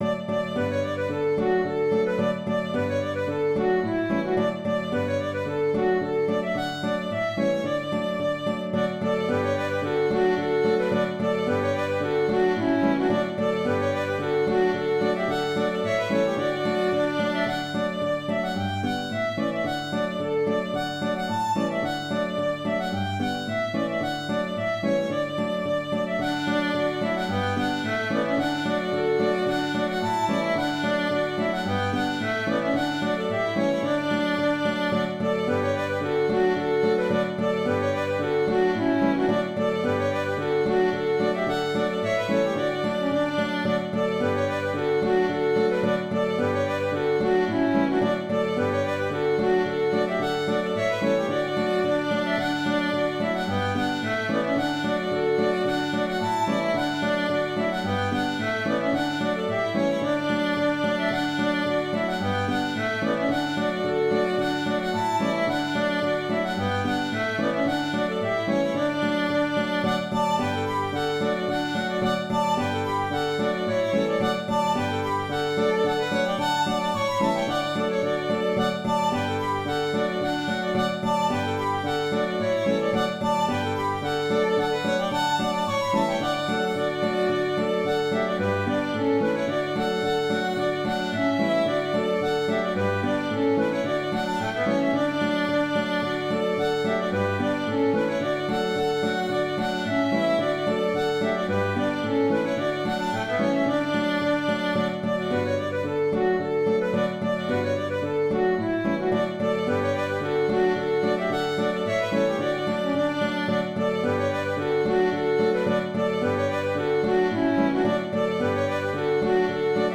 - La deuxième est une variation composée à partir du contrechant monté d’une octave, et qui sert de thème pour un autre contrechant. Le fichier audio reproduit ces deux parties, suivies de la reprise de la première partie. C’est une polka qui s’interprète souvent avec un tempo assez vif.